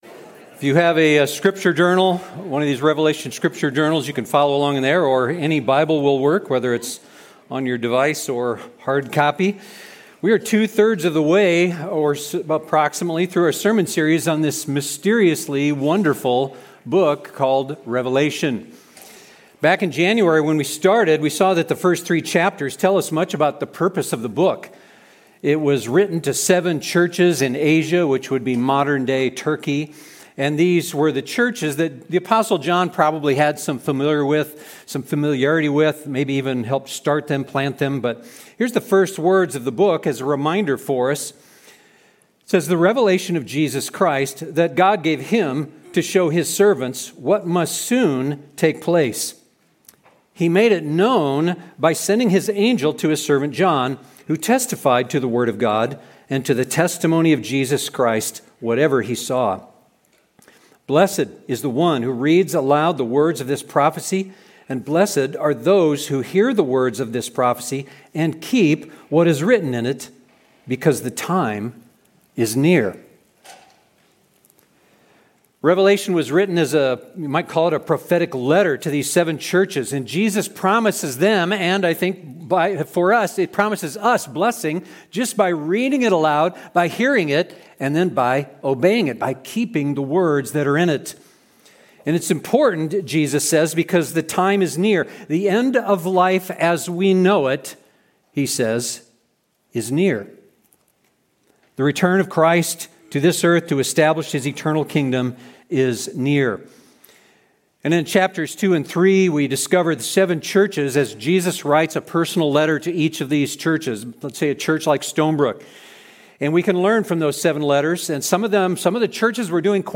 The Victory of the Lamb Revelation We are 2/3 of the way through our sermon series on this mysteriously wonderful book of Revelation.